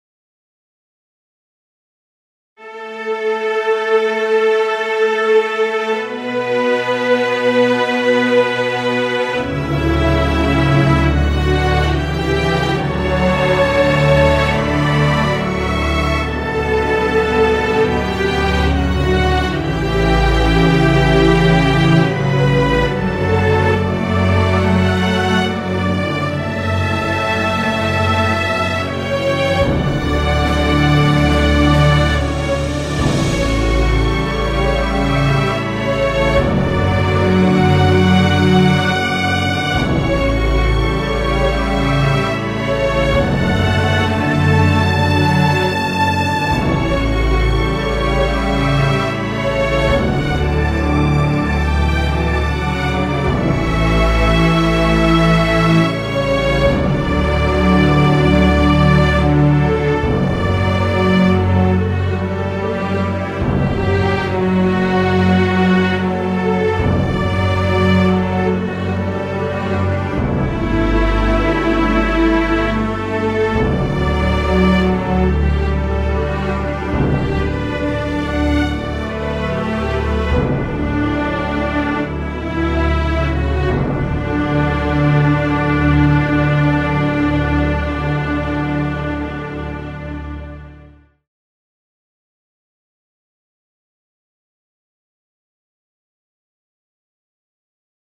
クラシックファンタジー明るい
BGM